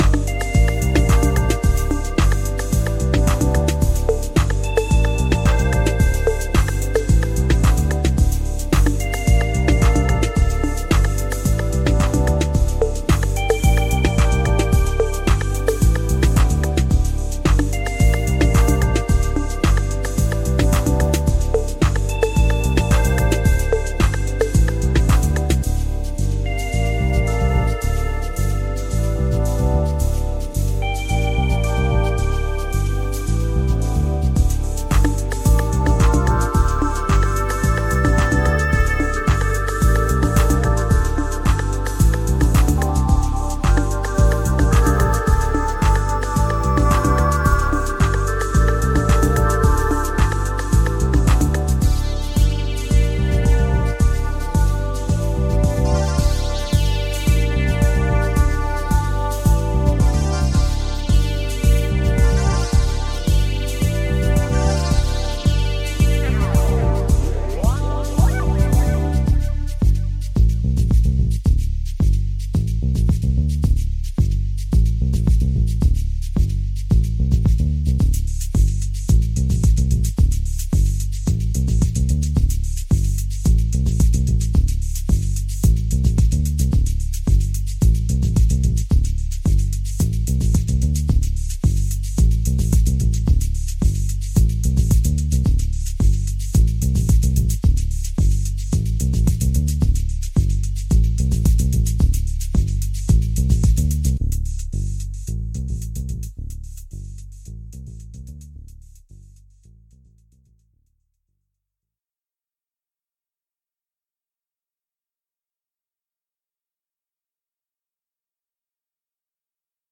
heady tech and minimal